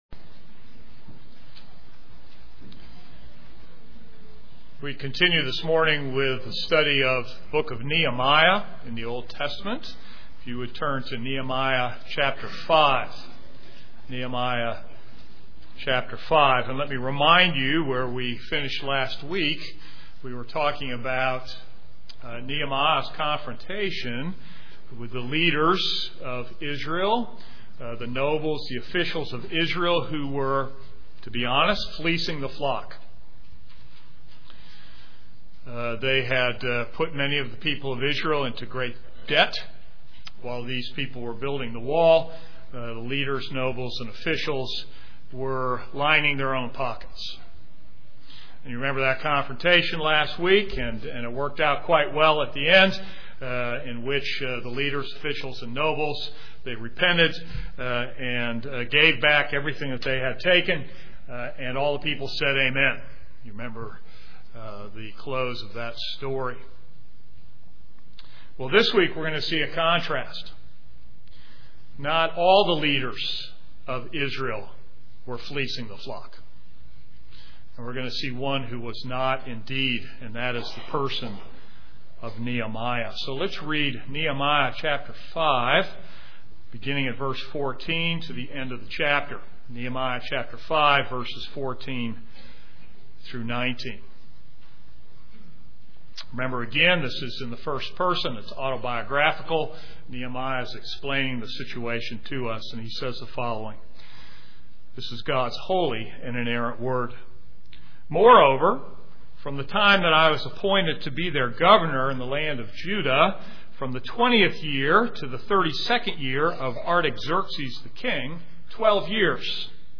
This is a sermon on Nehemiah 5:14-19.